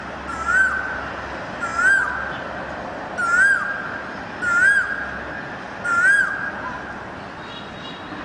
野外噪鹃鸟叫声